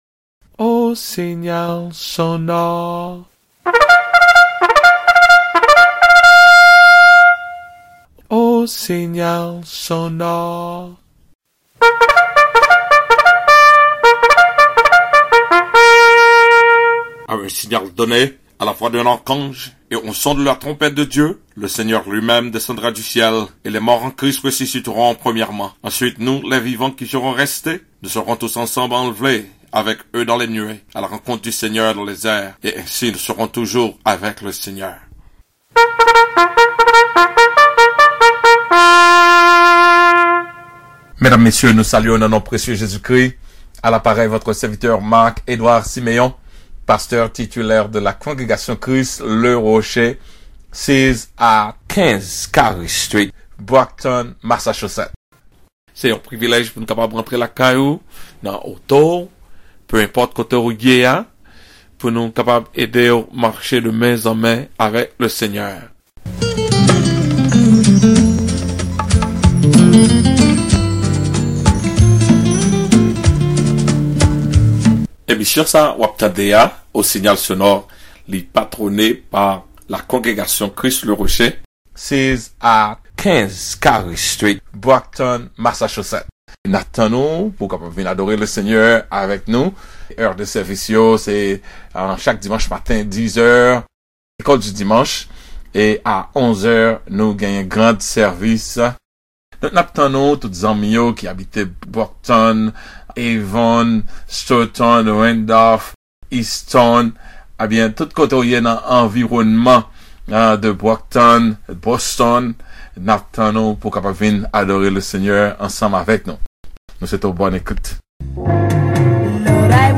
click: here to download the sermon: THE FATE OF THE CHURCH OF EPHESUS